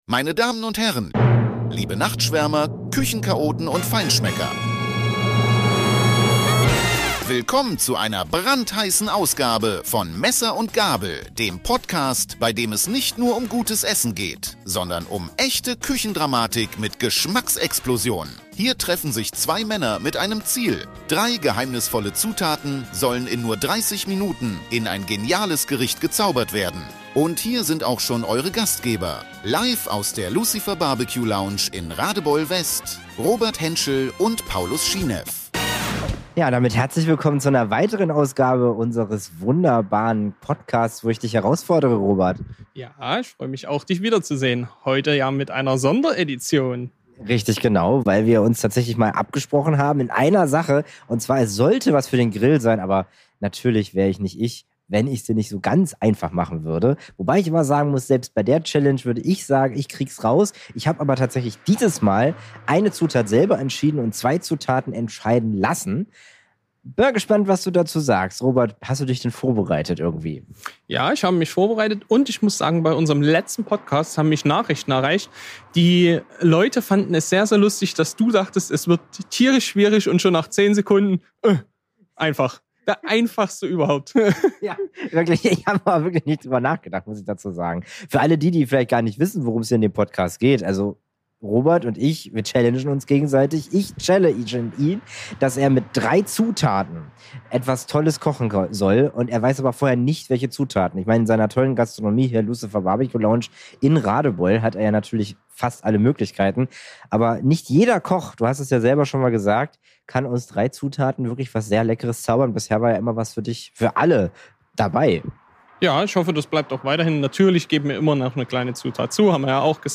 live aus der Lucifer BBQ-Lounge in Radebeul-West.